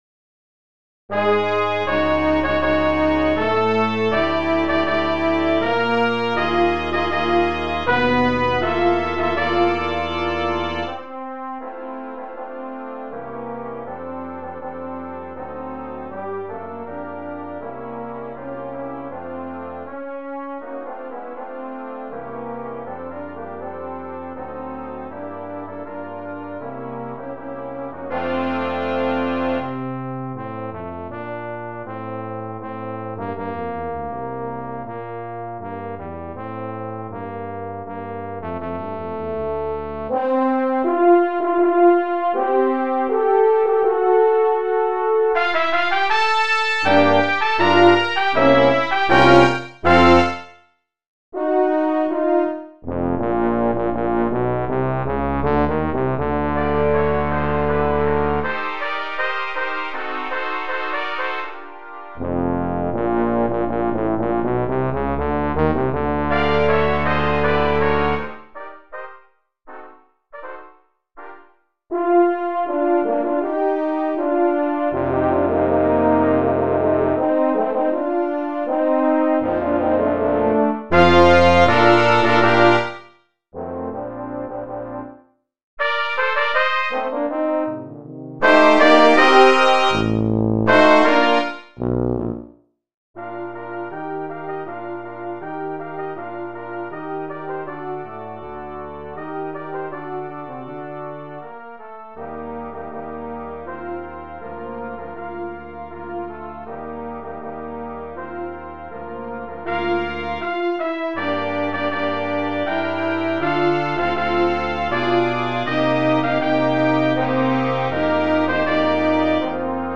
Voicing: Double Brass Quintet